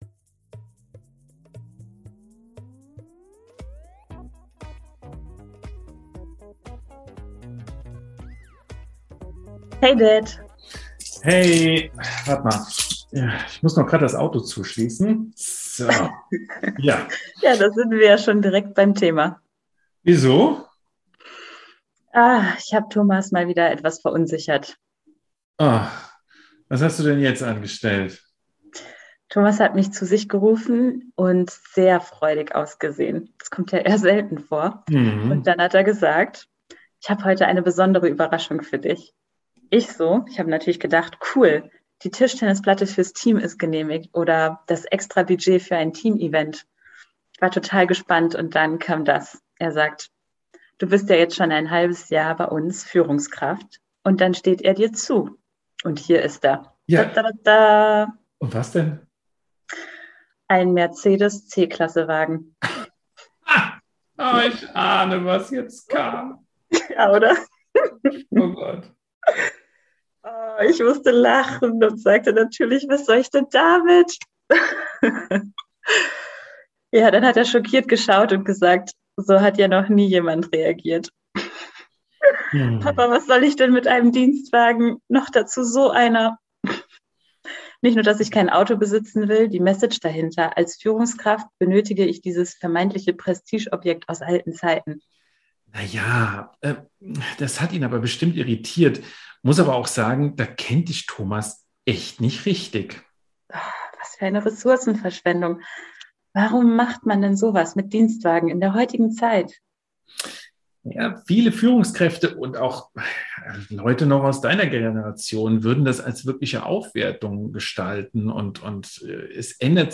eine erwachsene Tochter, die in einem eher traditionell geprägtem Unternehmen eine neue Anstellung begonnen hat und hierüber mit Ihrem Vater,
via Telefon diskutiert.